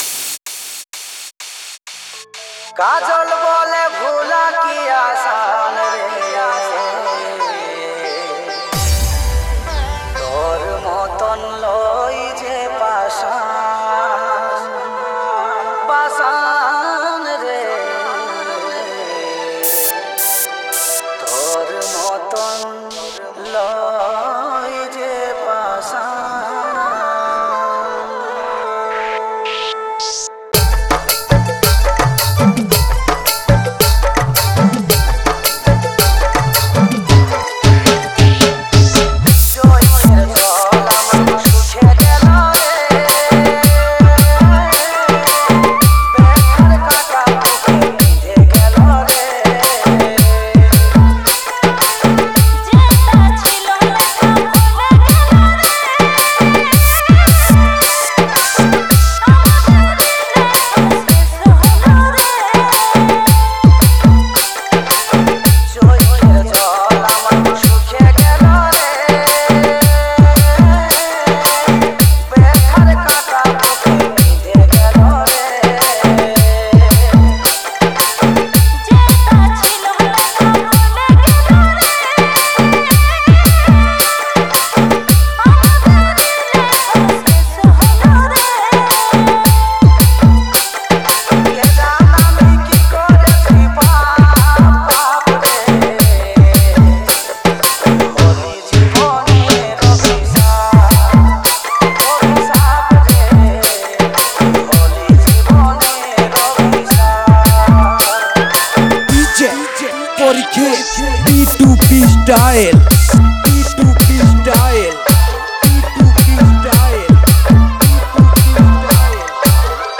Dj Remixer
Purulia Dj Remix